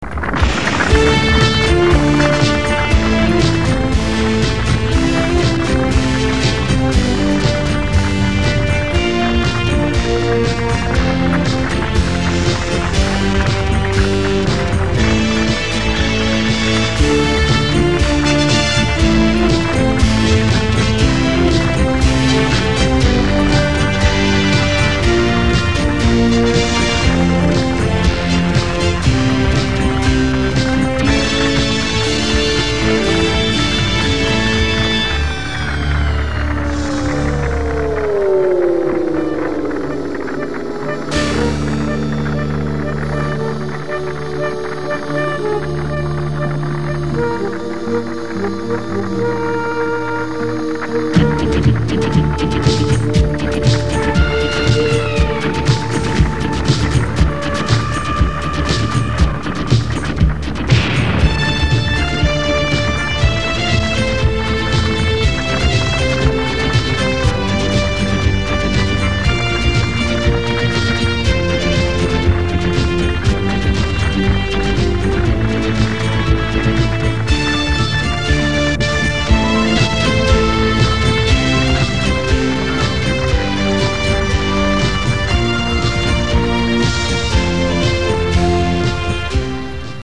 Hot on the heels of the stirring man title music